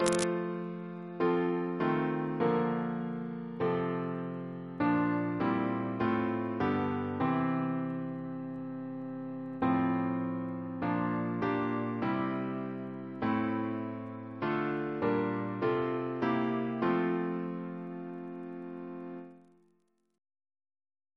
CCP: Chant sampler